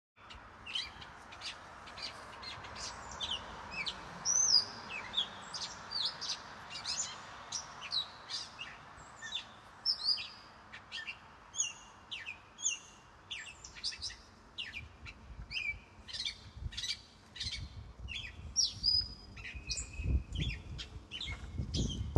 It is a very chatty bird, and while its ‘song’ is complex (diverse?) and even beautiful, it never seems to stop.
Cat-Bird-Call.mp3